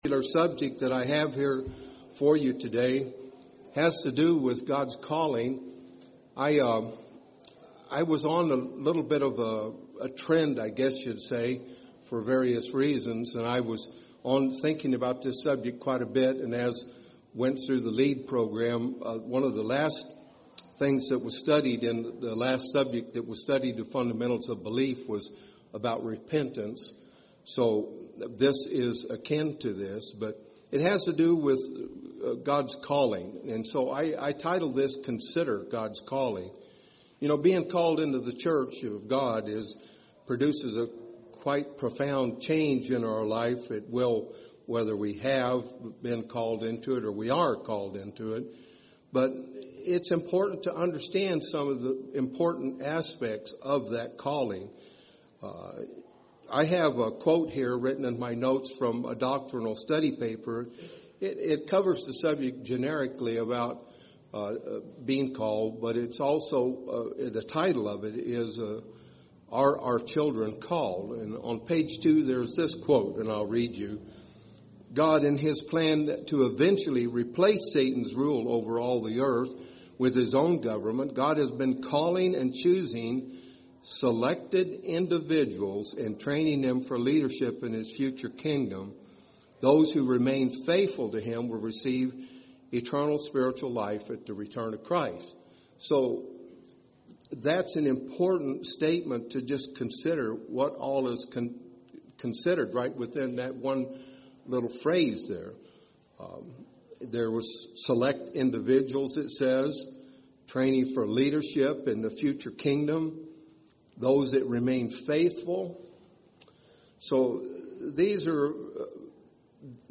Being called into the Church of God has/or will bring profound changes to our lives. The Bible study will review some very encouraging points to consider.
Given in Phoenix Northwest, AZ